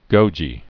(gōjē)